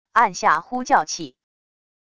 按下呼叫器wav音频